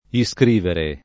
iscrivere [ i S kr & vere ]